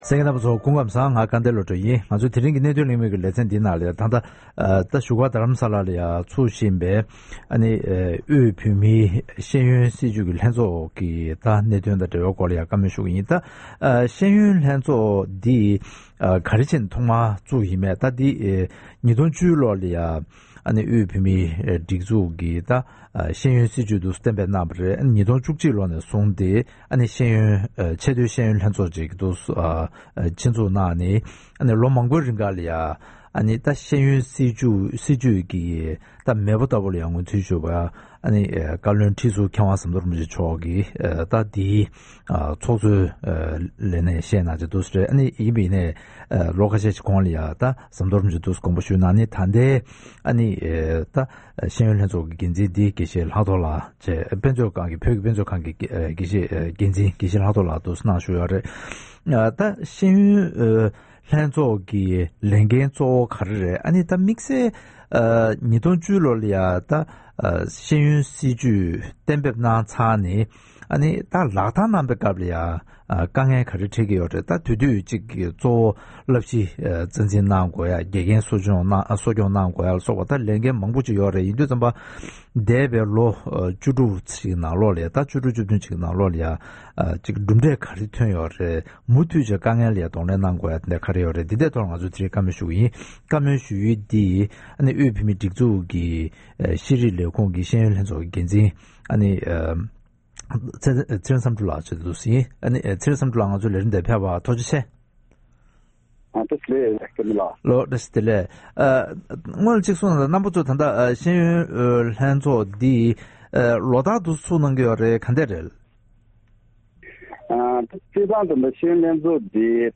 ༄༅། །ཐེངས་འདིའི་གནད་དོན་གླེང་མོལ་གྱི་ལེ་ཚན་ནང་།